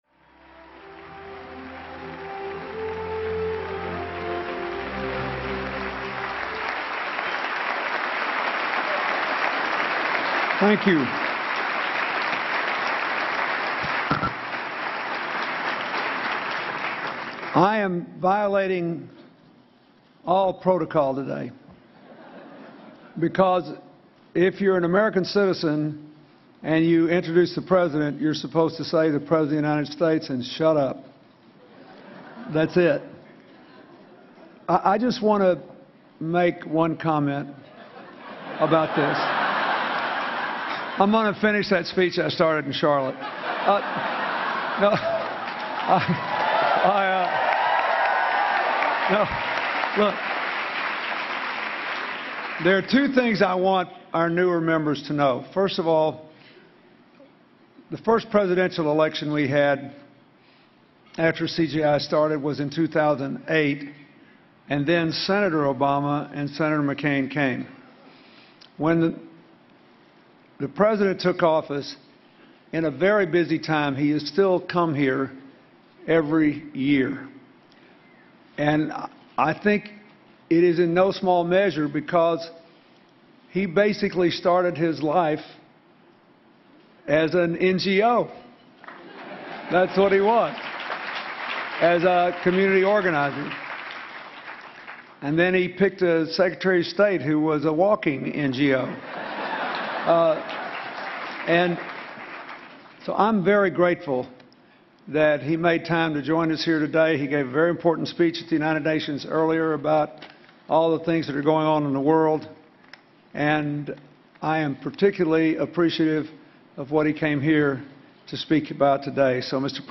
U.S. President Barack Obama speaks at the 8th annual Clinton Global Initiative (CGI) meeting in New York City